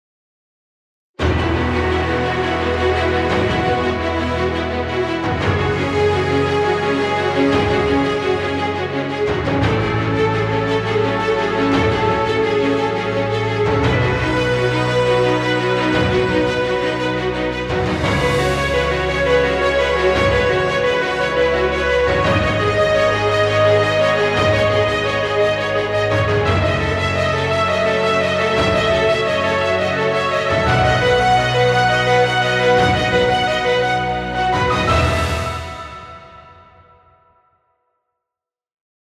Epic music, exciting intro, or battle scenes.
Cinematic dramatic music. Trailer music.